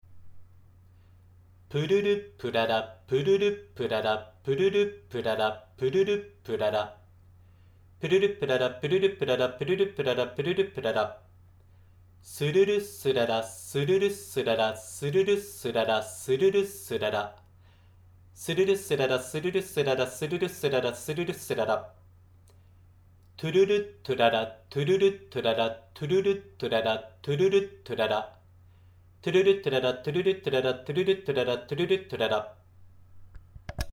ら行の滑舌トレーニング
ら行は、舌先を上の前歯の裏側の歯茎で弾くように発音する、はじき音」です。
◎リラックスして舌を動かす練習
1. 「プルル・プララ」×４
2. 「スルル・スララ」×４
3. 「トゥルル・トゥララ」×４